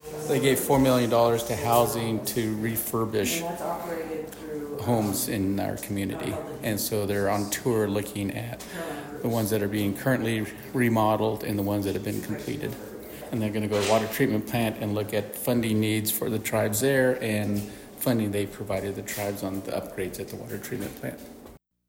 Warm Springs Secretary Treasurer and CEO Bobby Brunoe: